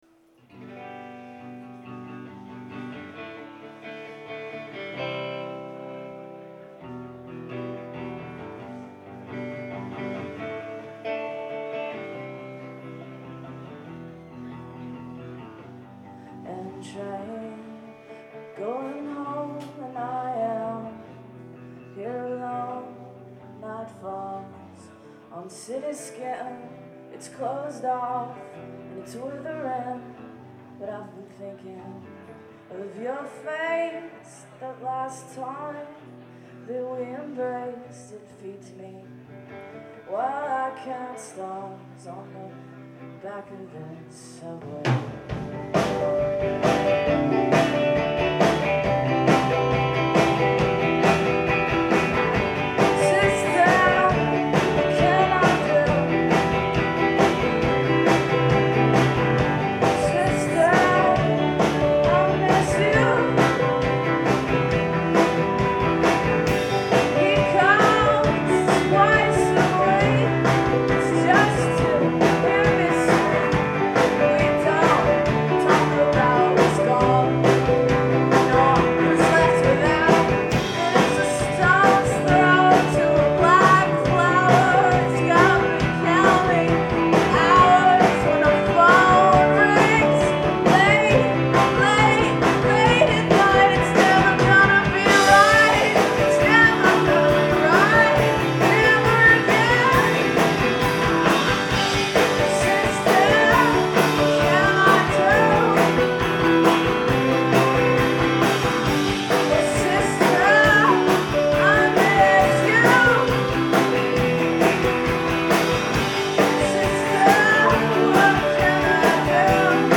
Live at Great Scott
in Allston, Mass.